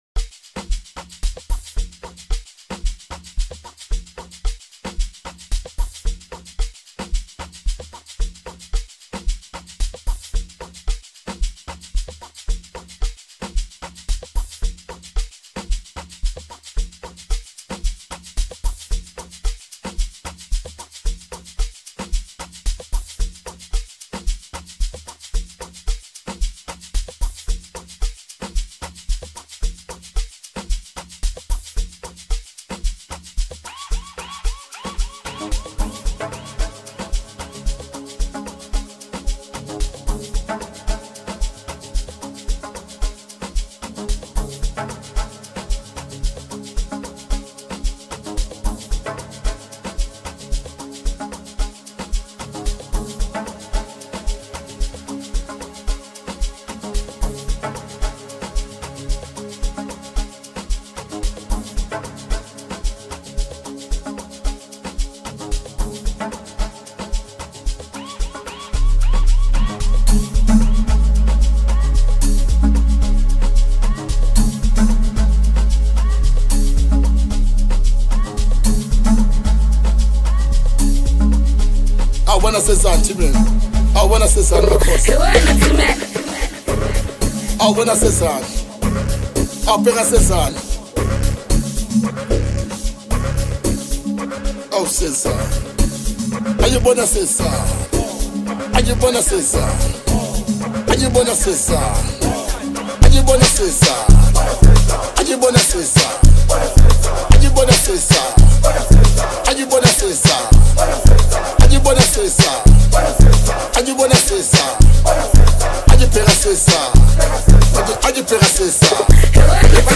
Home » Hip Hop » Amapiano » DJ Mix